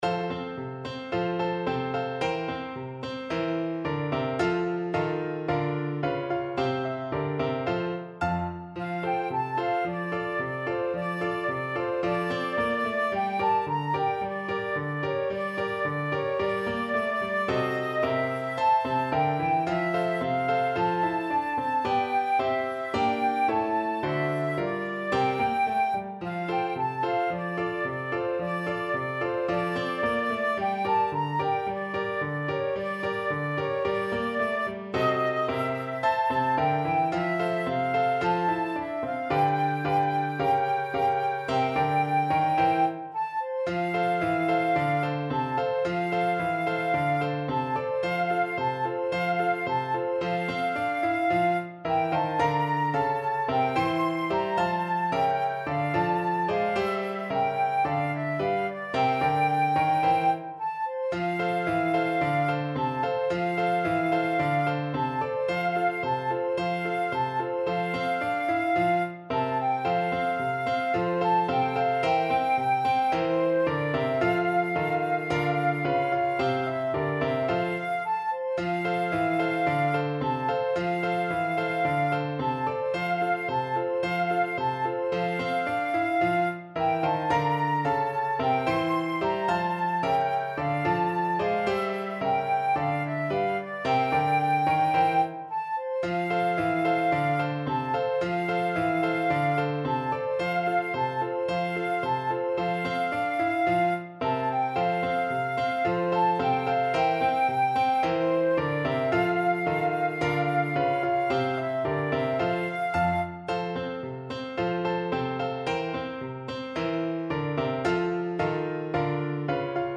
Moderato allegro =110
2/4 (View more 2/4 Music)
Classical (View more Classical Flute Music)